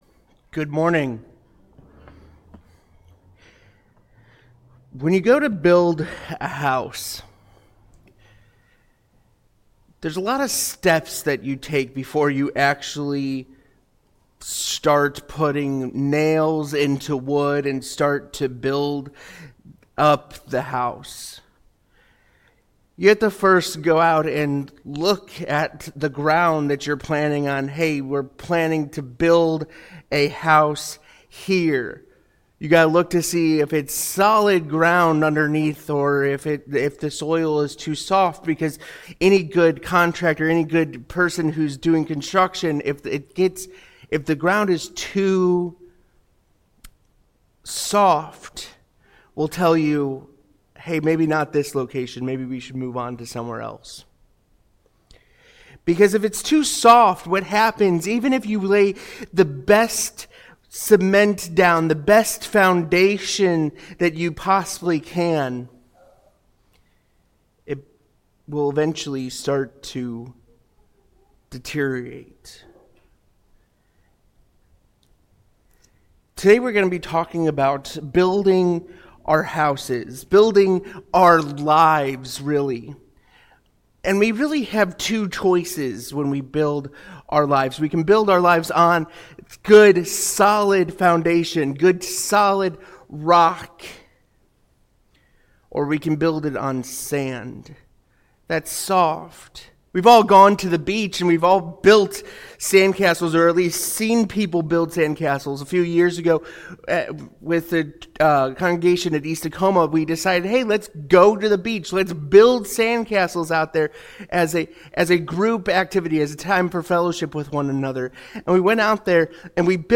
Sunday Sermons